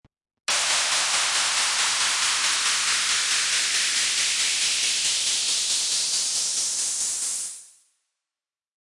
Sound Effects
Loud Noise Riser